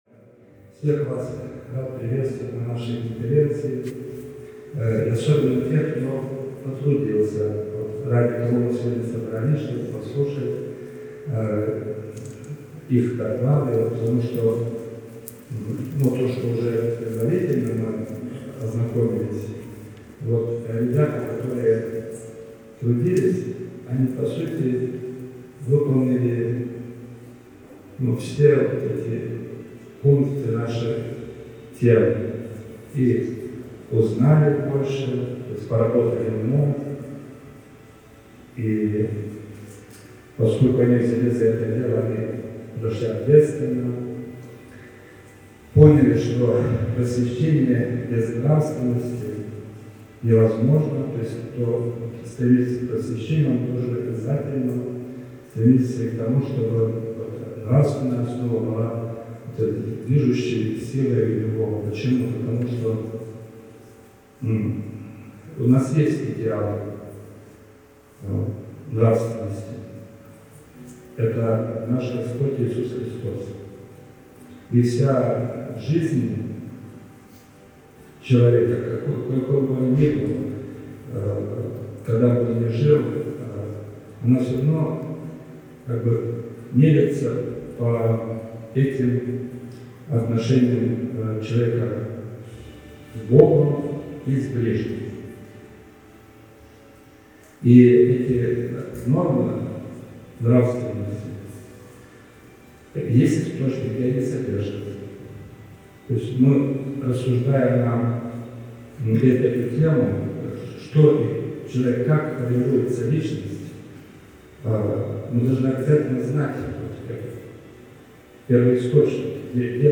Рождественские-чтения.mp3